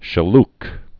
(shĭ-lk)